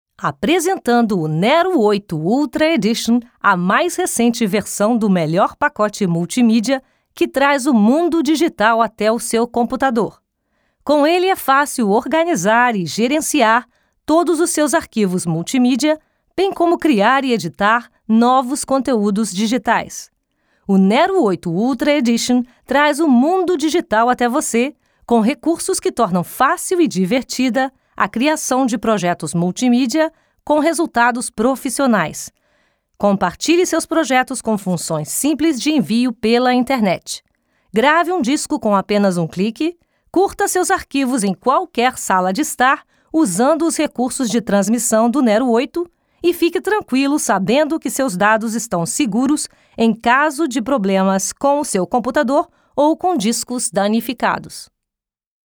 Portugiesich/Brasilianische Sprachaufnahmen von professioneller brasilianischer Schauspielenrin für Image, VO, Werbung und Ansagen.
Sprechprobe: Werbung (Muttersprache):